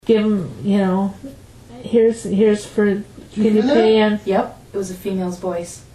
Female or child's voice. This was heard audibly by several people and recorded while we were all in the living room chatting.
femalevoice.mp3